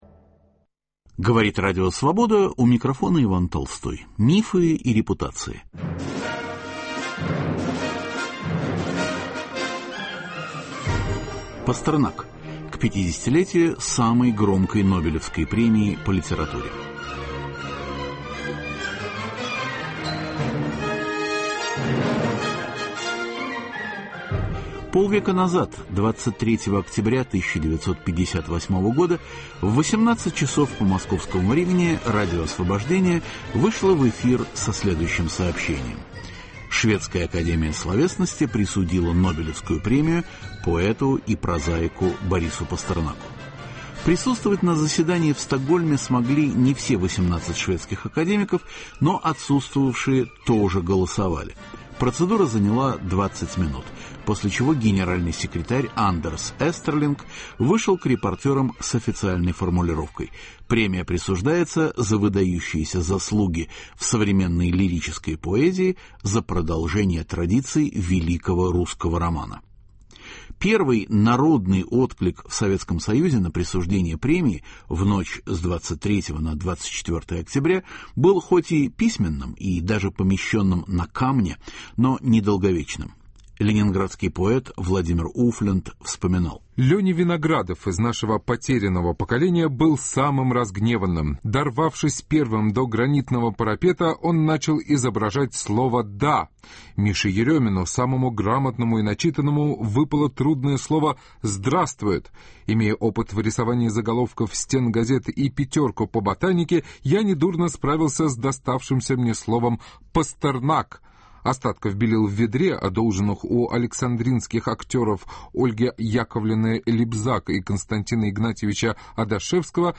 50 лет Нобелевской премии Борису Пастернаку. Драматические события того времени по архивным записям Радио Свобода. Роман "Доктор Живаго" в оценке литературной критики 1950-1960-х годов.